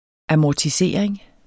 Udtale [ amɒtiˈseˀɐ̯eŋ ]